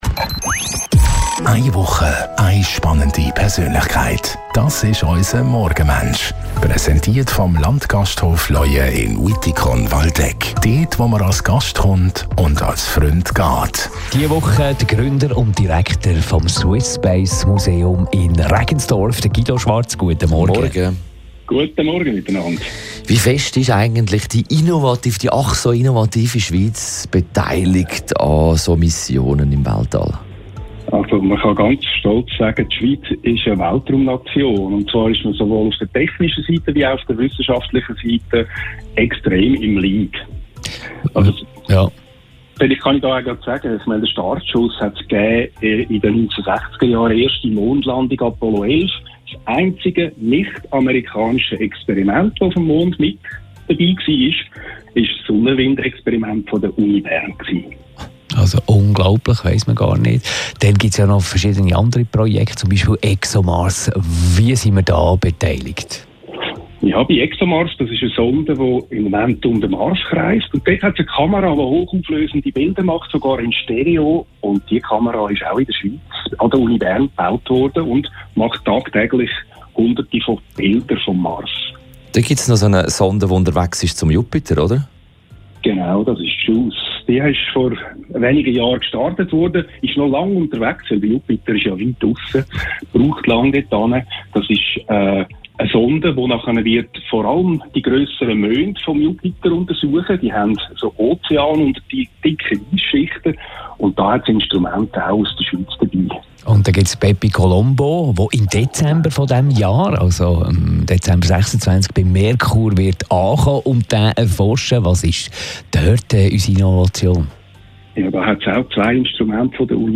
telefonieren jeden Morgen von Montag bis Freitag nach halb 8 Uhr mit einer interessanten Persönlichkeit.